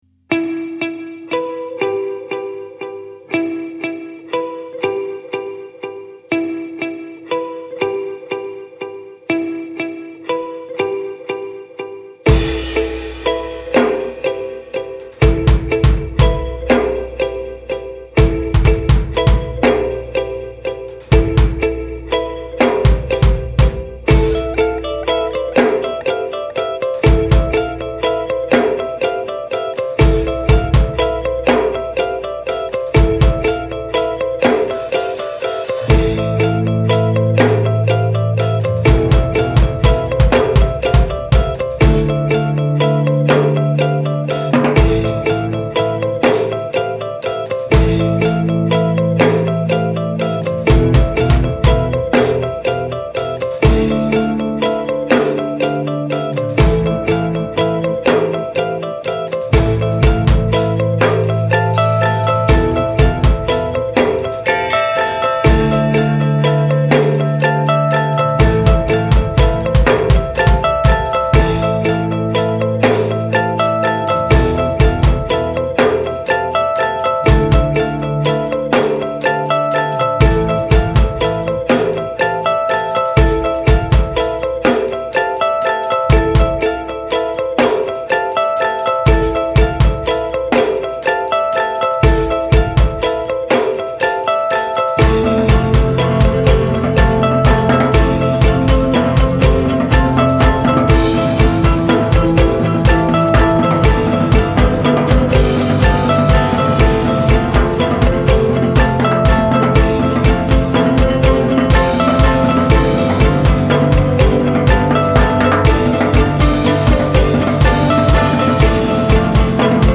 It’s the inception of flying in a spiral.